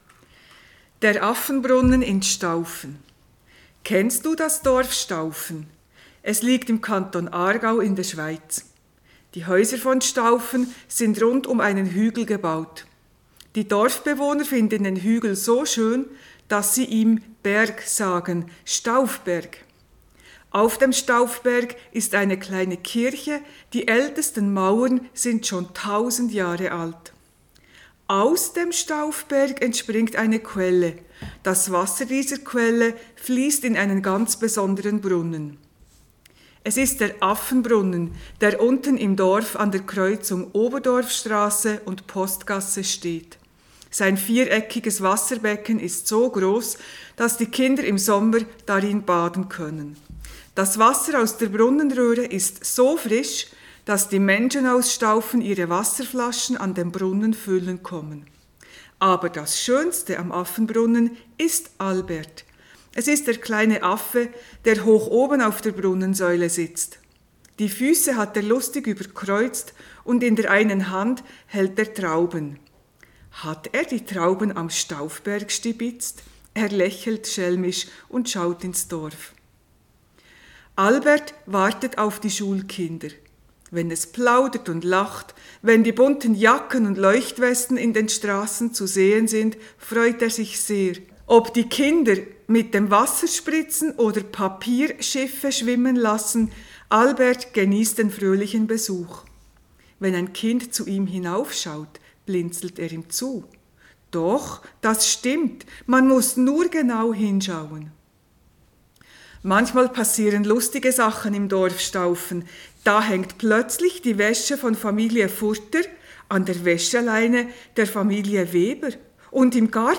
Die MP3-Geschichte ist eine super Ergänzung zum Zuhören für gross und Klein.